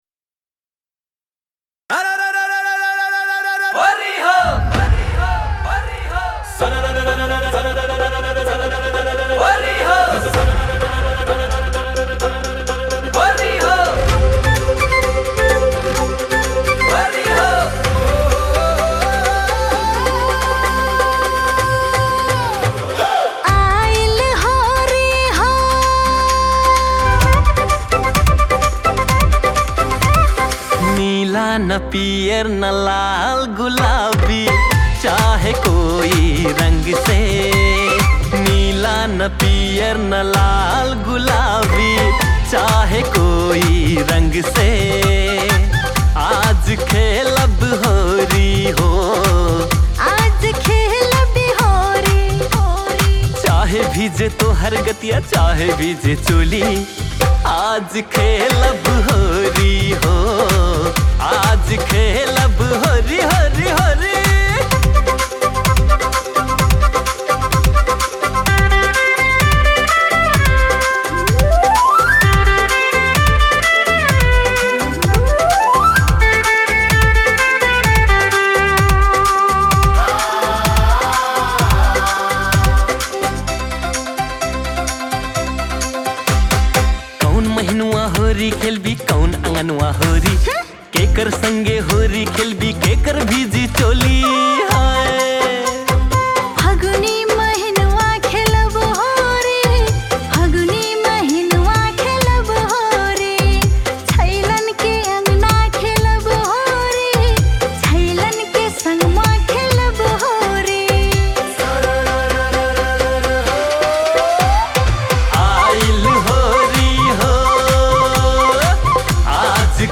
Tharu Holi Song